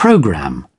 /ˈstrʌɡ.l̩/